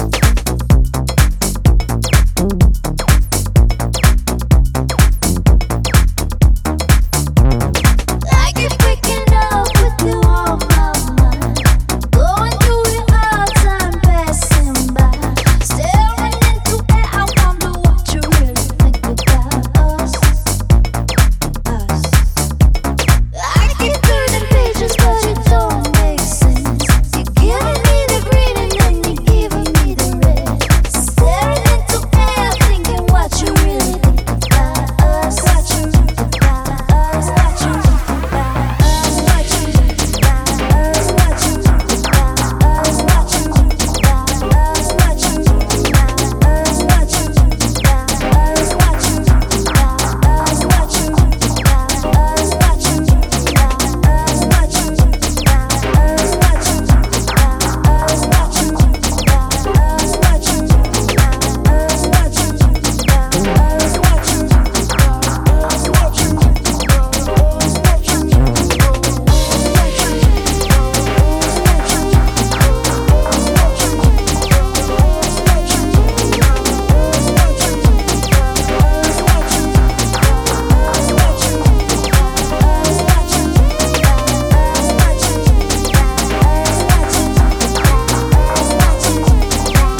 さすがと言いたいアナログな出音と豊潤なテクスチャーに惚れ惚れするシンセ・ファンク/ハウスに仕上がっています！